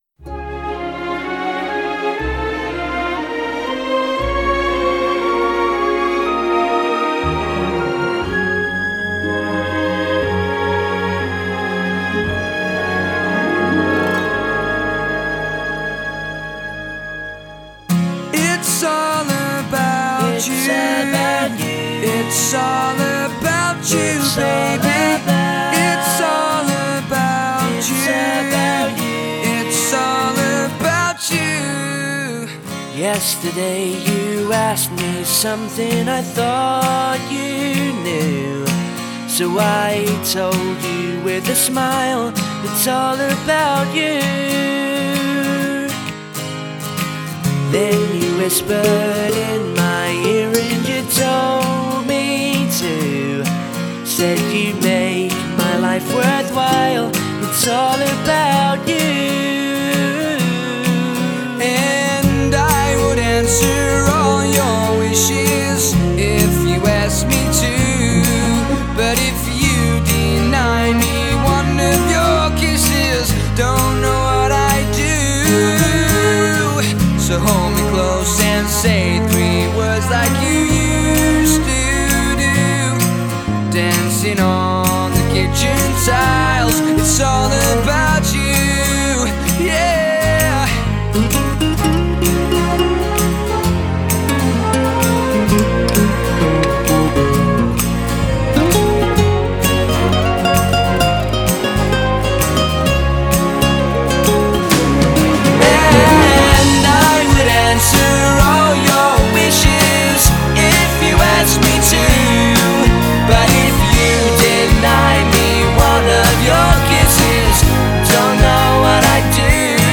записана с оркестром